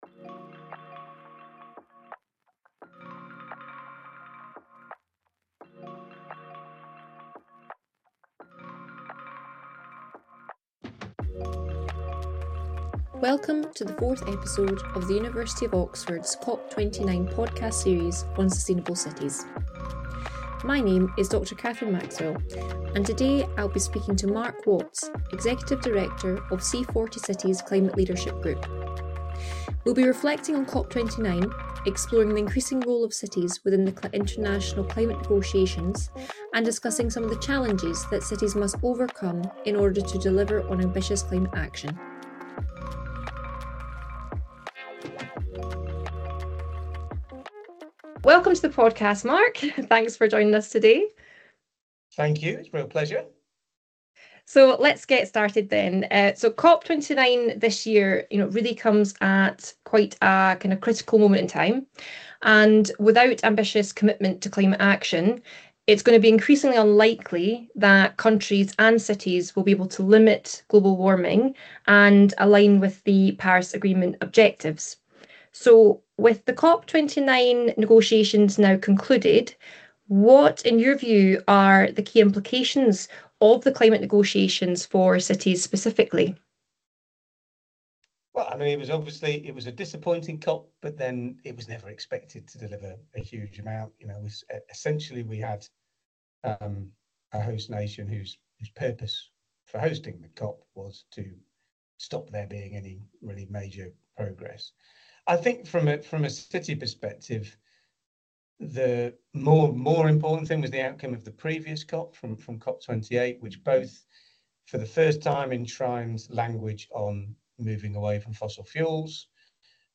This episode explores the key takeaways for urban areas, regarding the knowledge shared and new commitments (e.g.., finance for mitigation and adaptation). The discussion covers both progress made and key challenges that must be overcome to meet commitments.